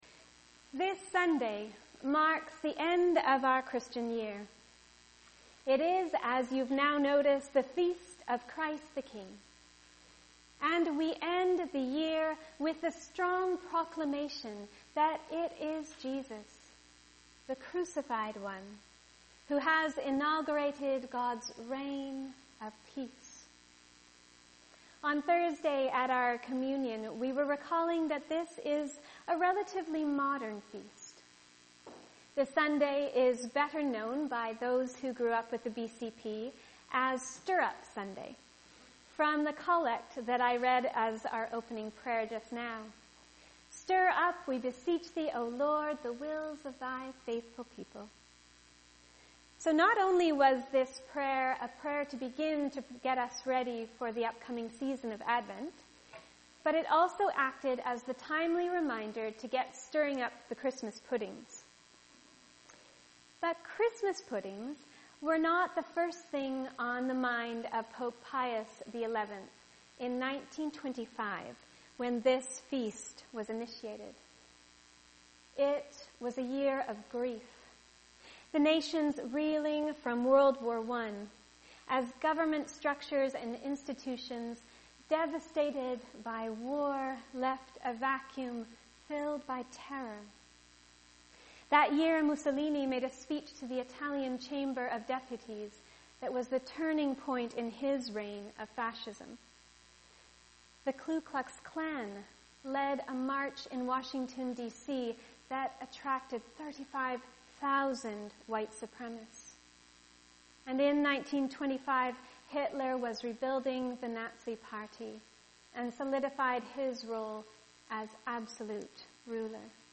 Sermons | the abbeychurch